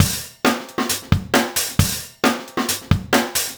drums02.wav